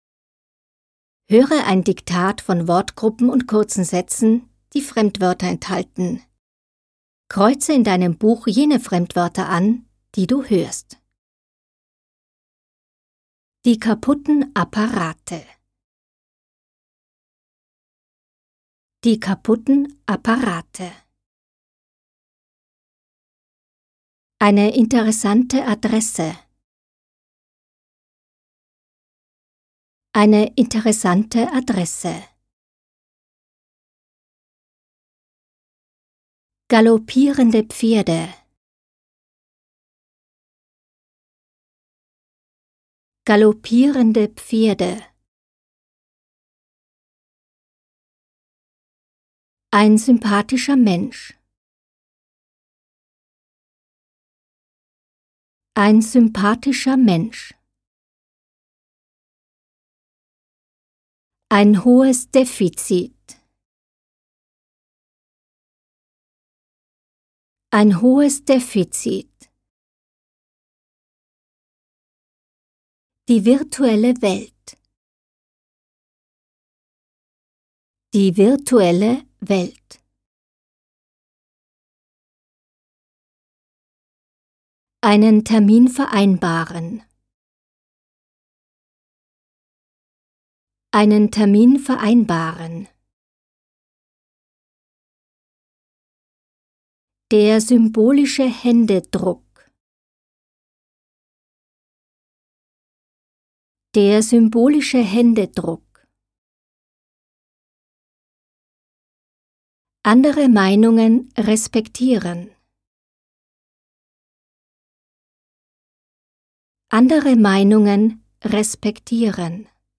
01_hpt_KD3_Trainingsteil_M4_R9_Diktat_final.mp3